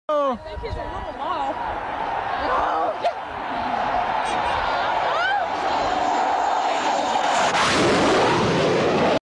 Fighter jet flyby breaks camera